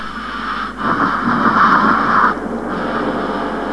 alien.wav